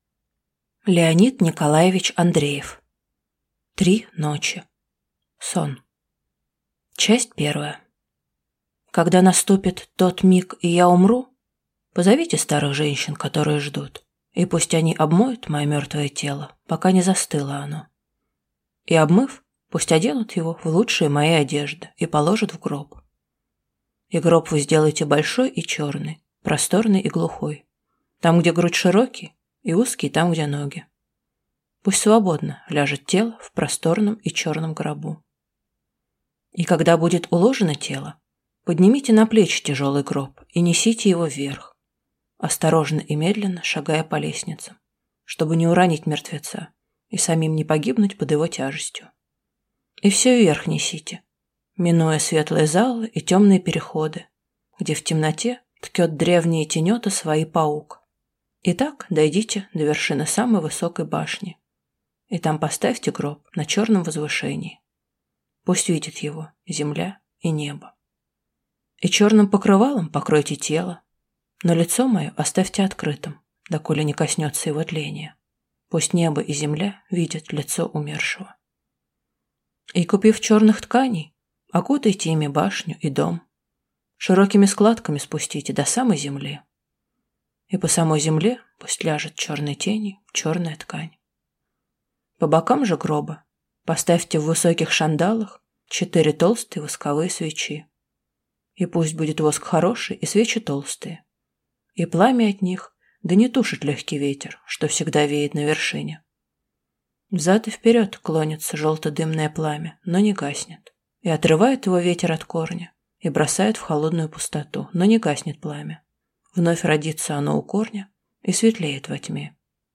Аудиокнига Три ночи | Библиотека аудиокниг